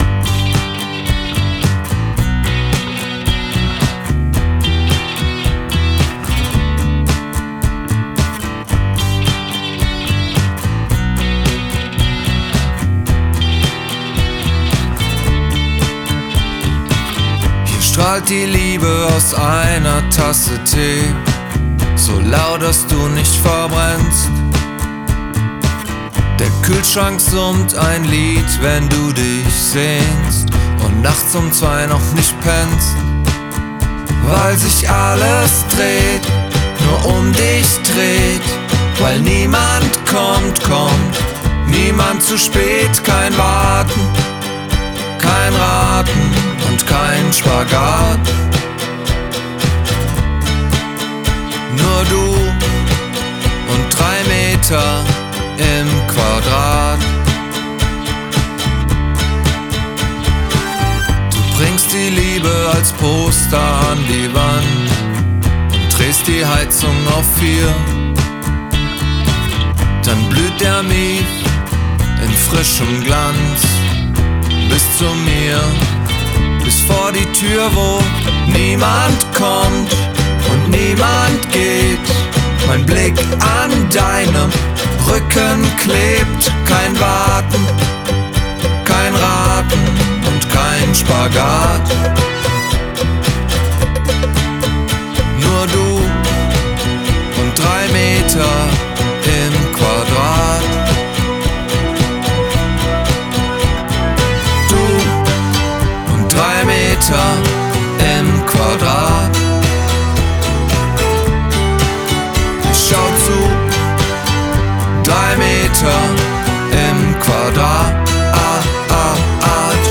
"Drei Meter im Quadrat" (Indie/Pop, deutsch)
Ich hab es tendenziell lieber satt und voll anstatt dünn und kühl. Auch hab ich versucht, ein bisschen was Kreatives einzubauen bzw. rauszunehmen, Filterfahrten usw. Ich gebe zu, dass mir zum Ende hin manche Töne des Synth etwas weh tun, weil sie voll auf einem unpassenden (nicht zum Akkord gehörenden) Ton liegen.